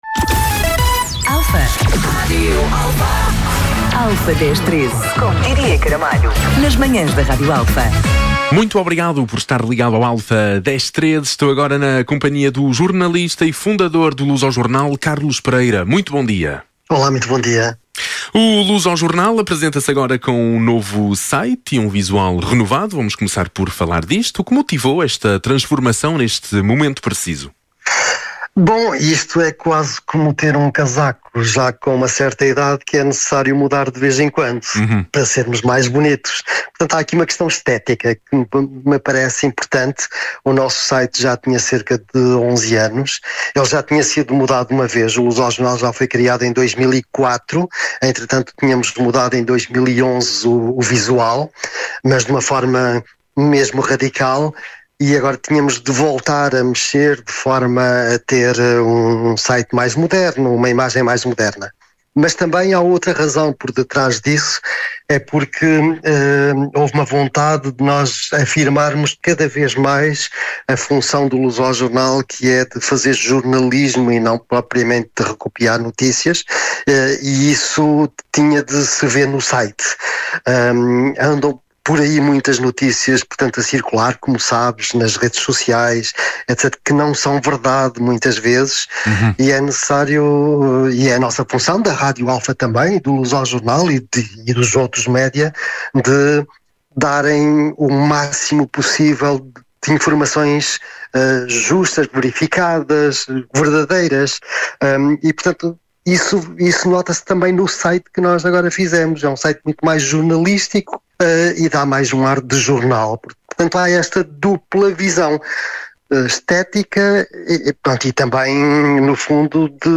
Entrevista.